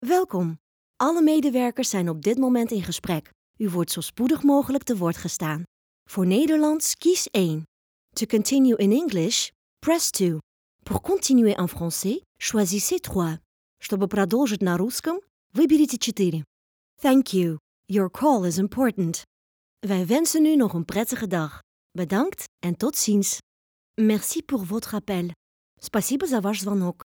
Distinctive, Playful, Versatile, Friendly, Warm
Telephony